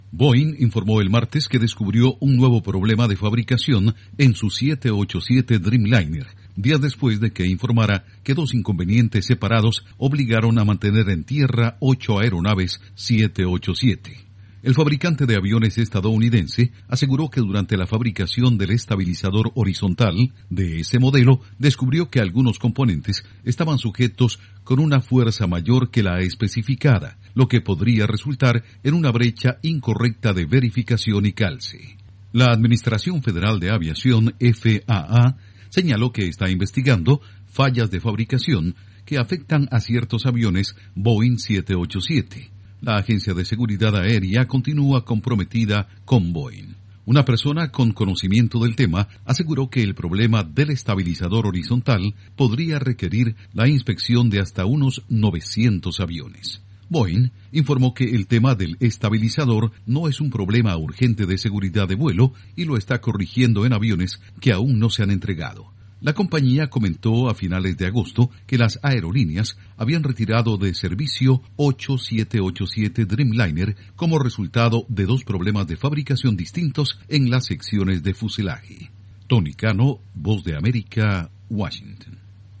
Boeing encuentra nuevos problemas en producción del 787 Dreamliner. Informa desde la Voz de América en Washington